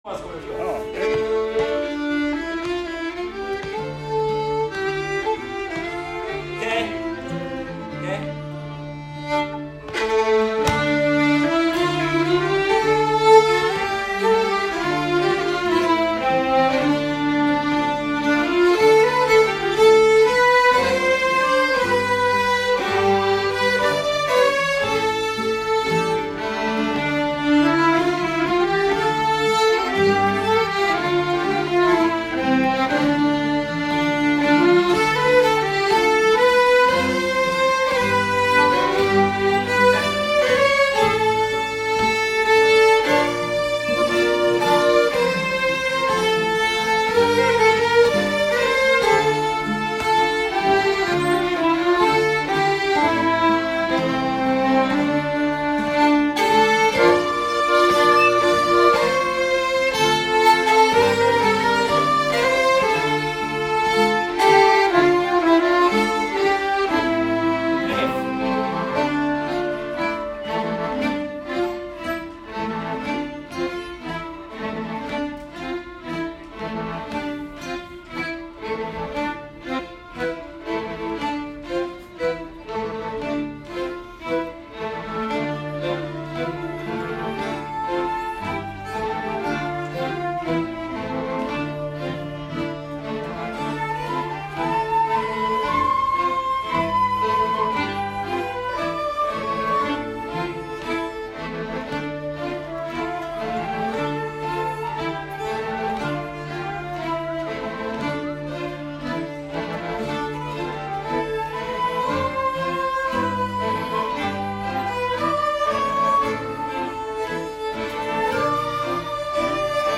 Som låt nummer 4:a spelade vi bägge lagen ihop “Karolinermarschen” efter Eric Sahlström. Här kommer en ljudfil med en inspelning från en träningskväll i Norrtälje.